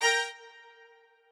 strings8_26.ogg